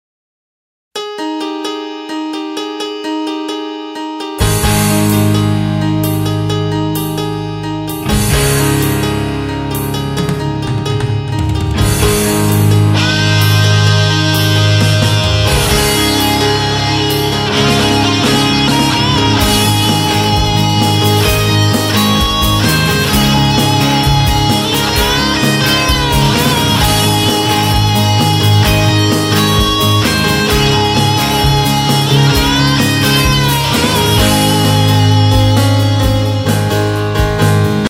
Drone when Recording Distorted Guitar
Alright so I double tracked and also changed to the green channel crunch mode rather than the high gain red channel on my amp to try to get rid of some of the "scratchiness".